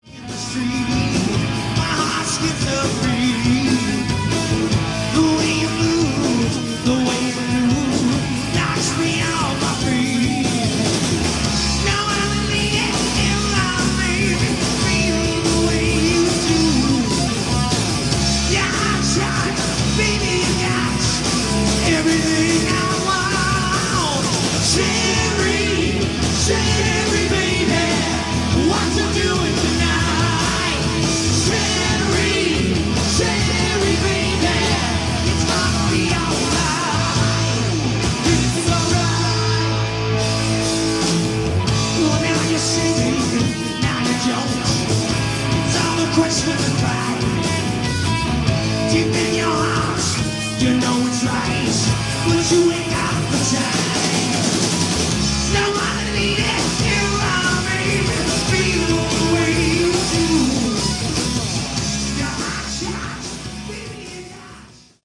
Category: Hard Rock
lead guitar, backing vocals
lead vocals
bass, backing vocals
drums, backing vocals
live 4/4/87